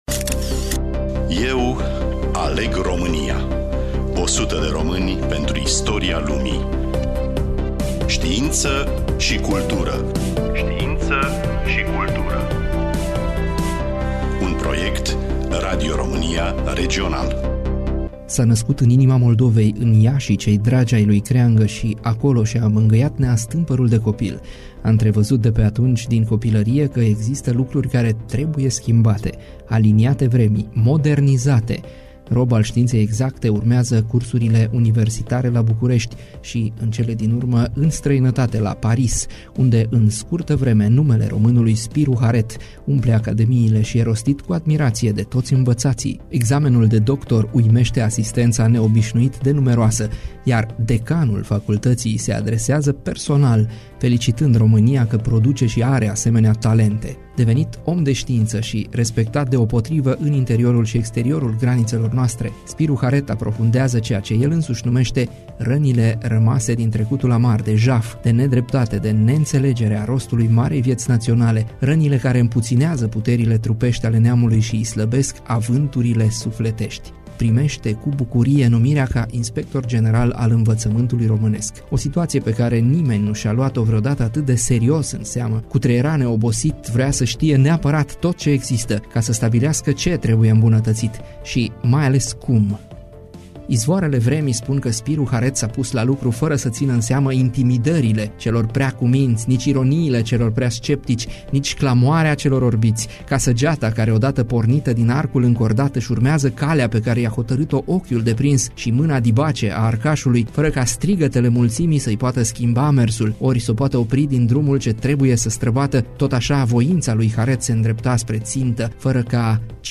16-nov-Spiru-Haret-RADIO-EDITION_01.mp3